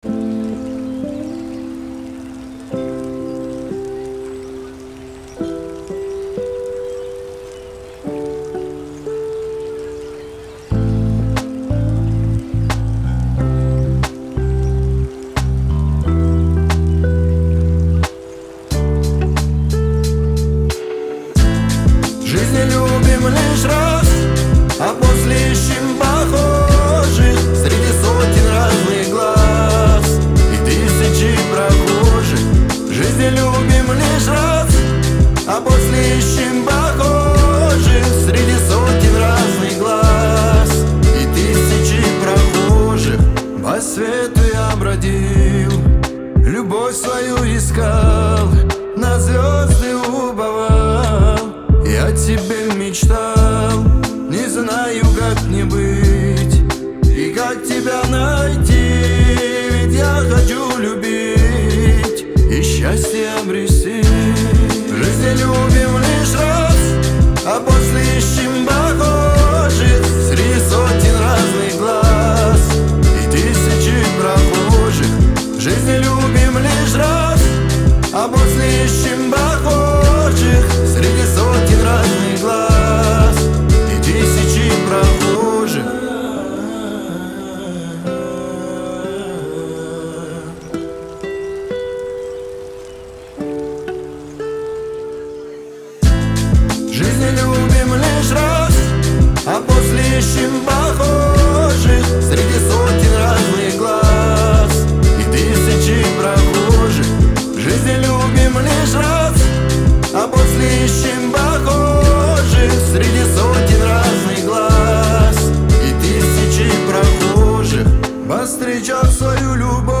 ХАУС-РЭП
грусть